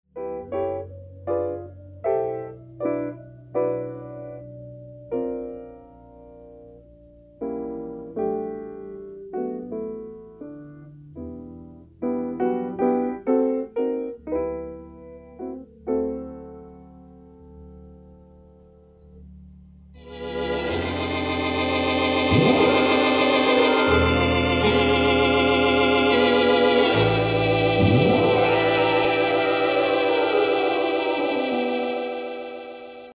No Leslie speakers were used!
jazz pianist/organist